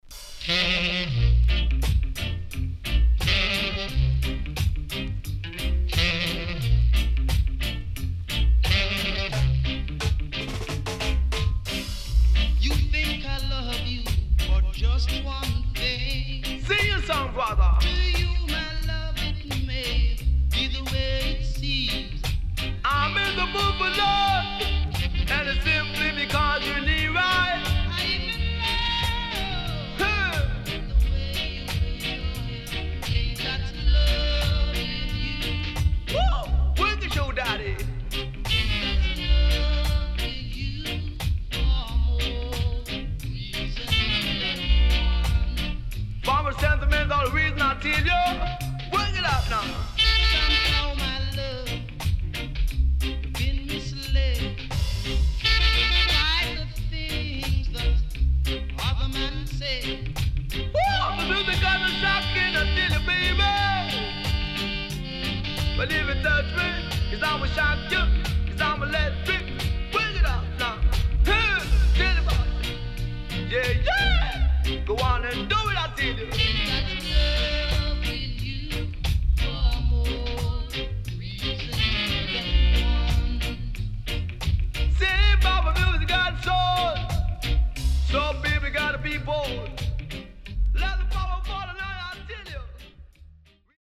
往年の名曲の数々にTalk Overした傑作盤
SIDE B:少しチリノイズ入りますが良好です。